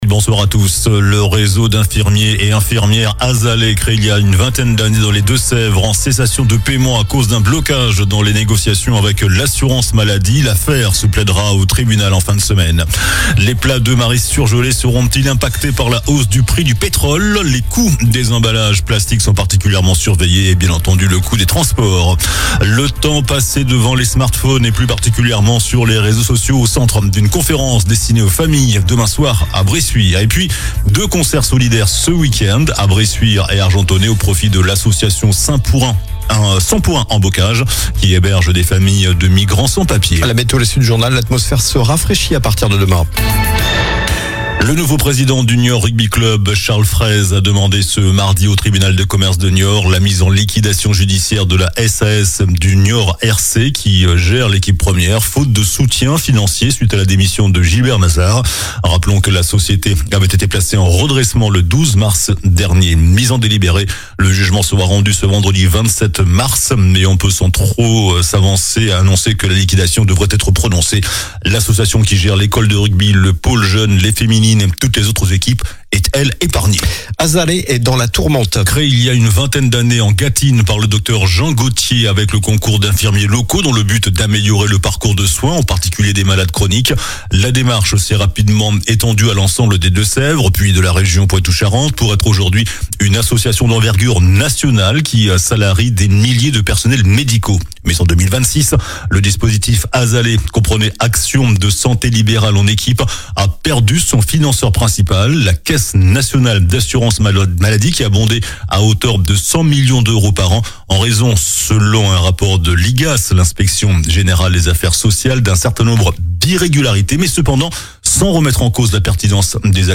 JOURNAL DU MARDI 24 MARS ( SOIR )